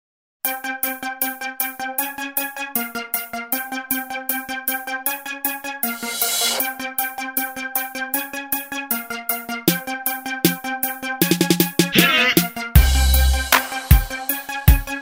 Dance, Hip-Hop